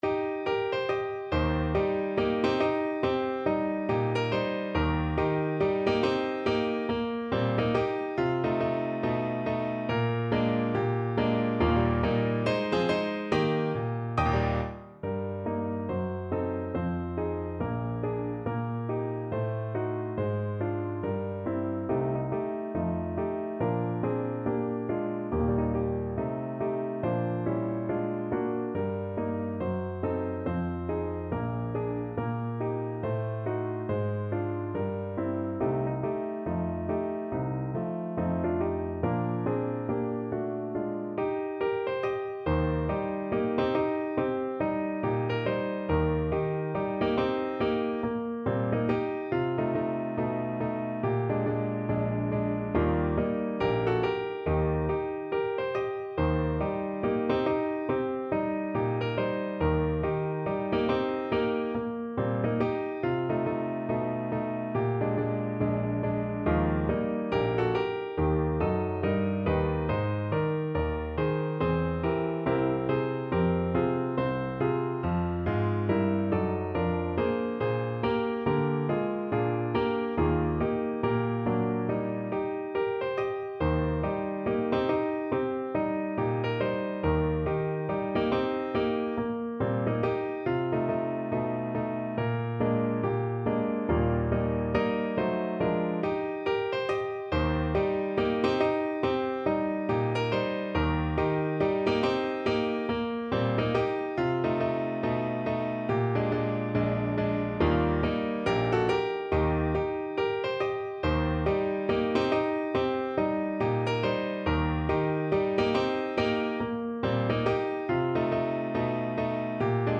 = 70 Moderato
Pop (View more Pop Voice Music)